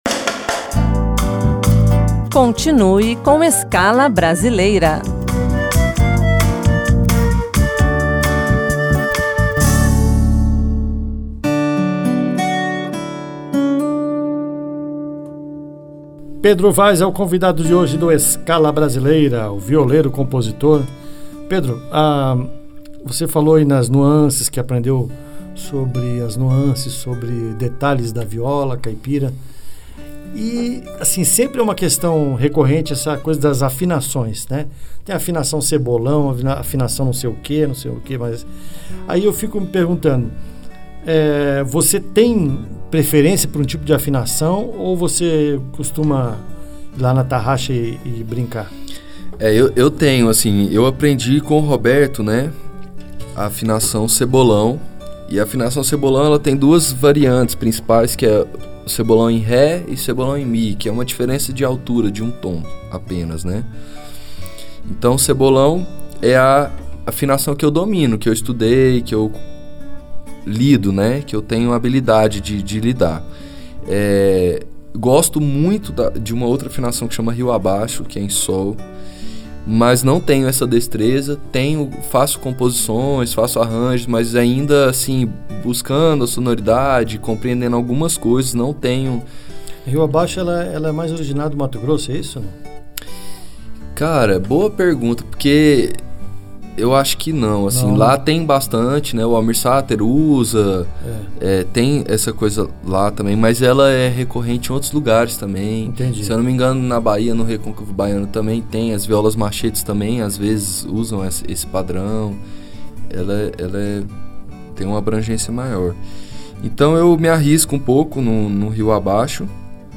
viola caipira